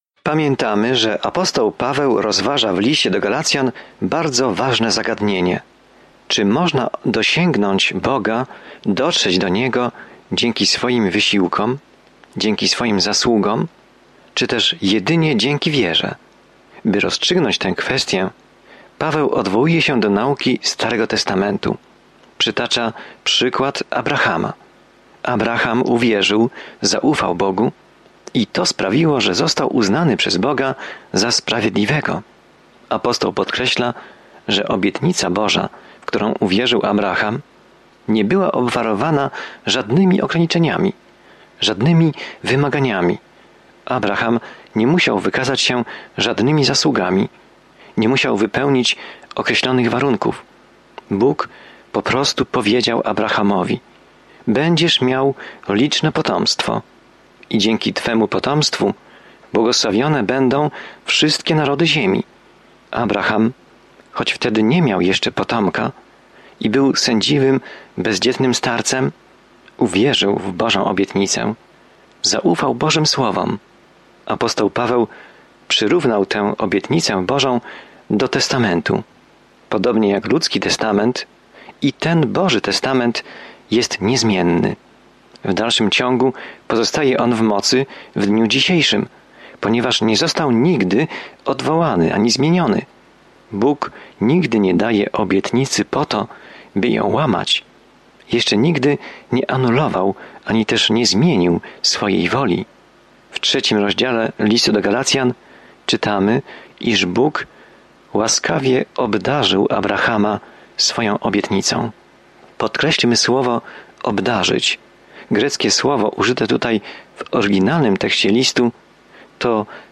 Pismo Święte Galacjan 3:19-22 Dzień 8 Rozpocznij ten plan Dzień 10 O tym planie „Tylko przez wiarę” jesteśmy zbawieni, a nie przez cokolwiek, co czynimy, by zasłużyć na dar zbawienia – takie jest jasne i bezpośrednie przesłanie Listu do Galacjan. Codzienna podróż przez Galacjan, słuchanie studium audio i czytanie wybranych wersetów słowa Bożego.